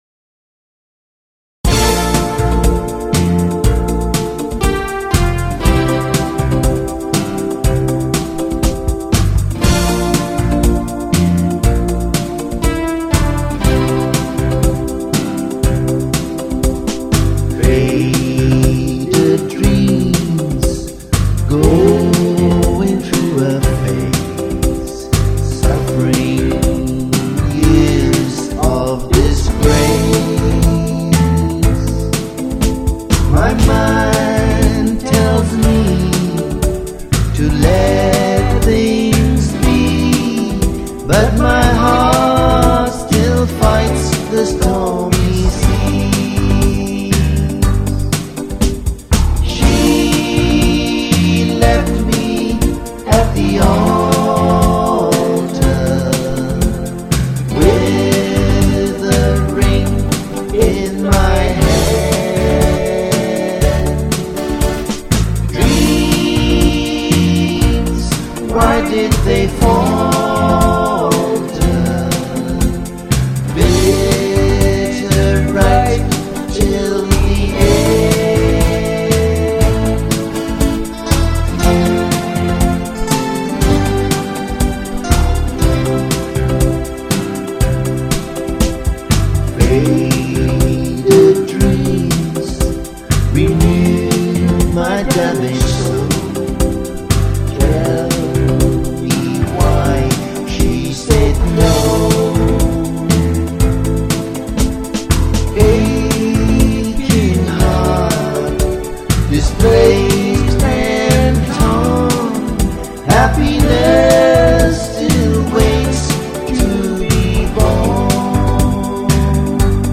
wrote and sang this together at Dingle, Ireland on Midsummer's Day